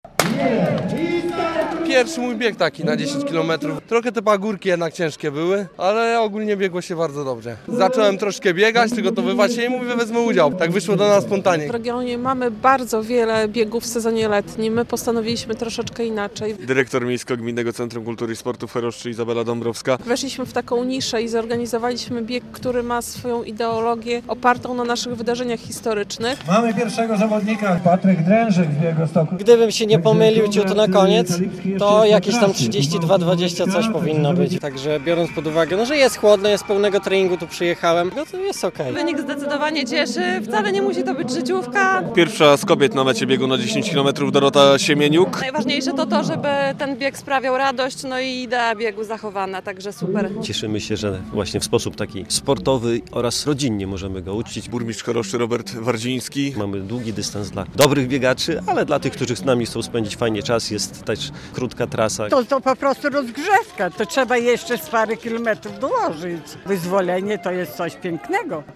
Bieg z Okazji Wyzwolenia Choroszczy - relacja